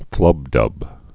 (flŭbdŭb)